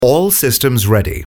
The  parlour that talks to you…
Hear VoiceAssist for yourself! Click below…